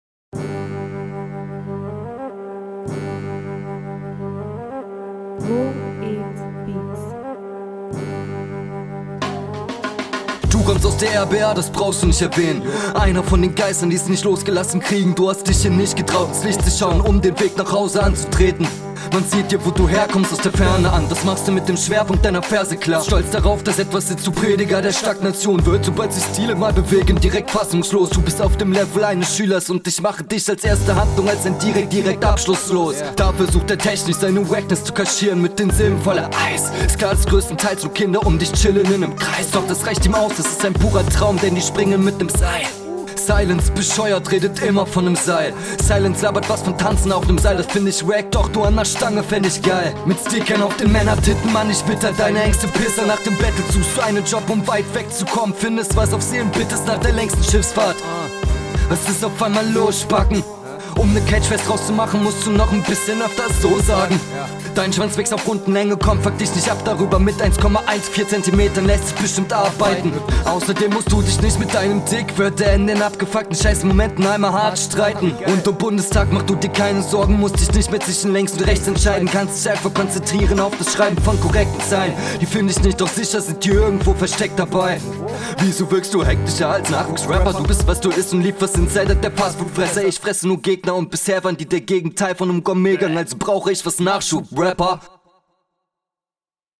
Wieder mit der mische rumgespielt puuh jetzt klingst du wie ein robotor, die mische ist …